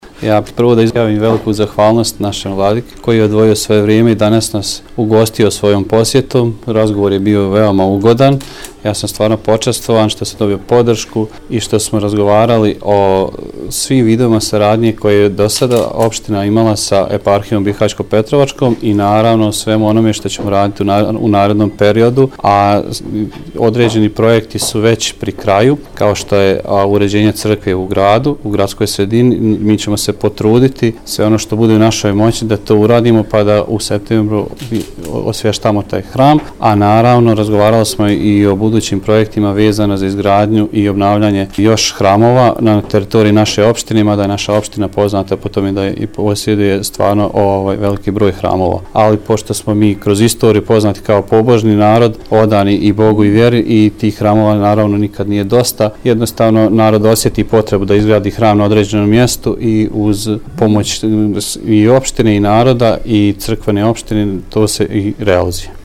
U nastavku poslušajte izjavu…